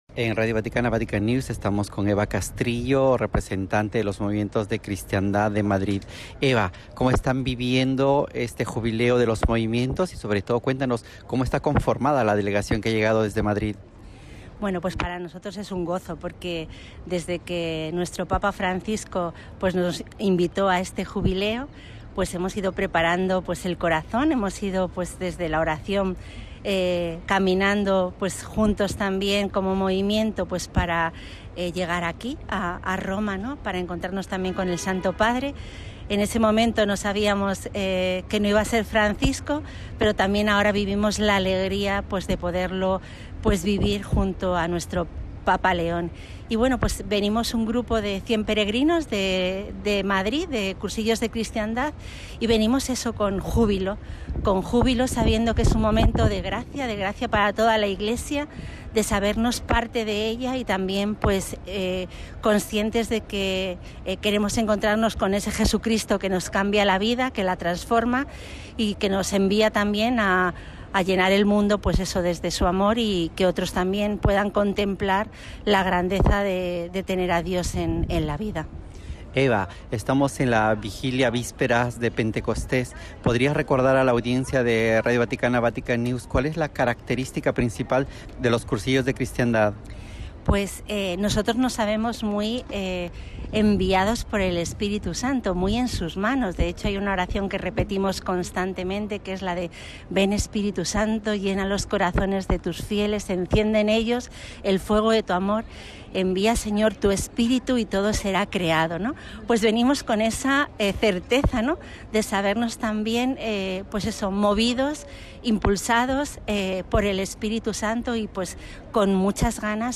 AUDIO. Testimonio de peregrinos que participan en el Jubileo de los Movimientos